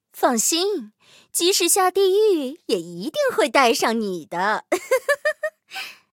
IS-4中破语音.OGG